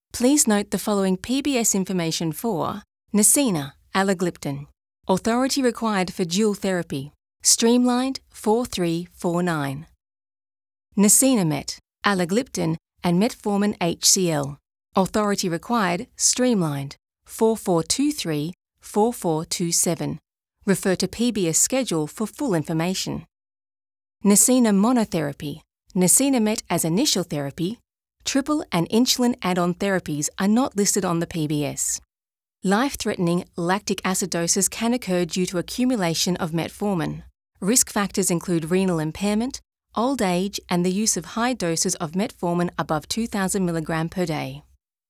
DrivetimeRadio - The Healthcare Professional Podcast | Medical Narration